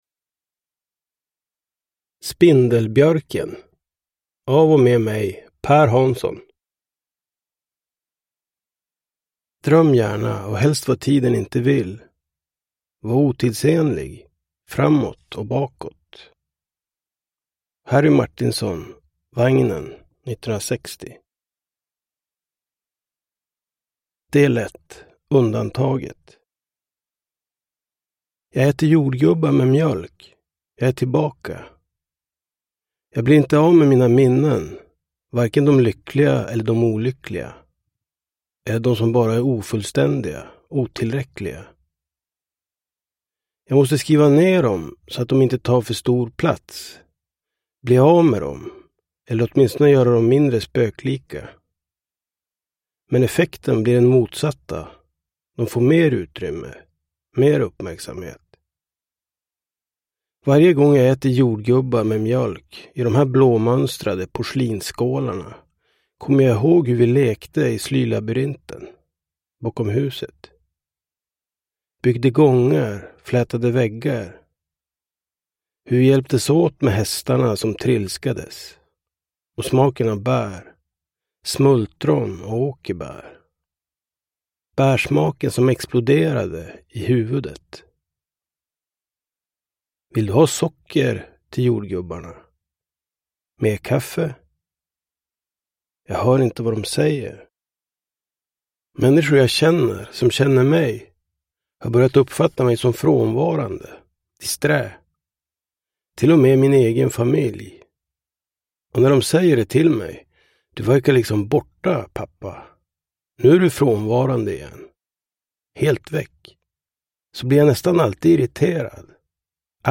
Spindelbjörken – Ljudbok